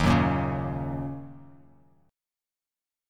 D#m7 chord